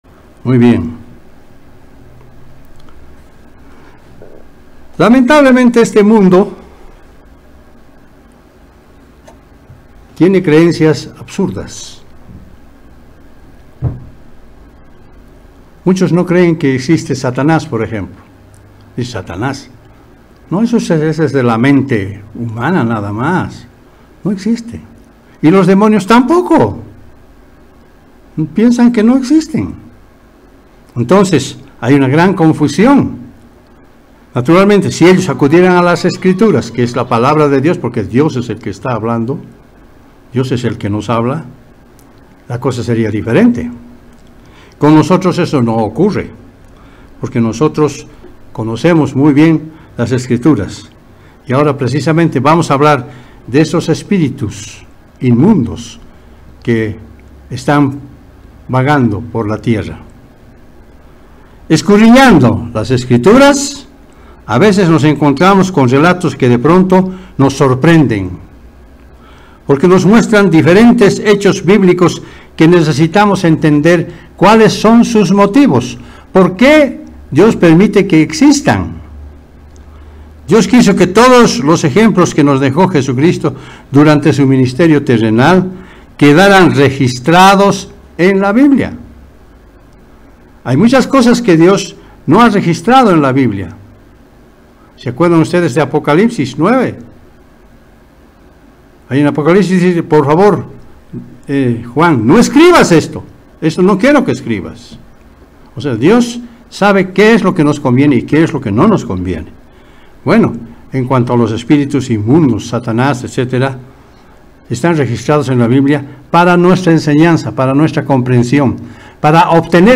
El mundo espiritual es una realidad, y pese a que se le subestima, es importante conocerlo para protegerse de él. Mensaje entregado el 20 de mayo de 2023.